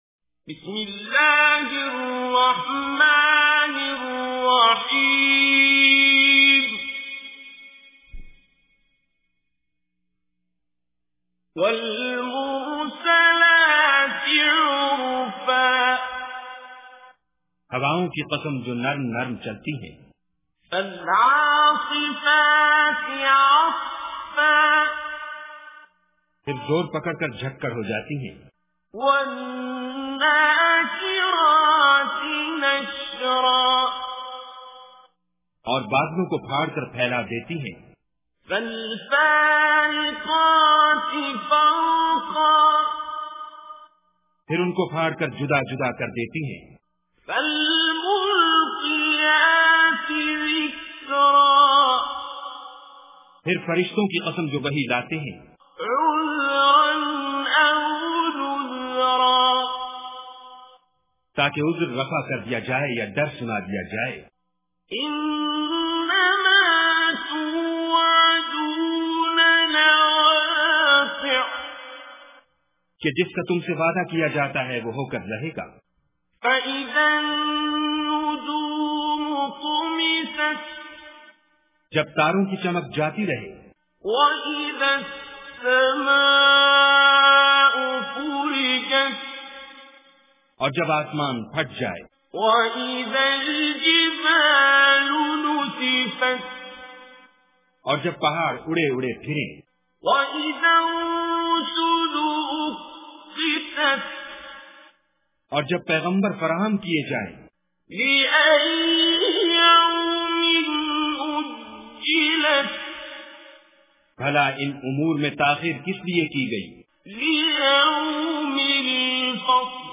Surah Mursalat Recitation with Urdu Translation
Surah Mursalat is 77 Surah or Chapter of Holy Quran. Listen online and download mp3 tilawat / Recitation of Surah Mursalat in the beautiful voice of Qari Abdul Basit As Samad.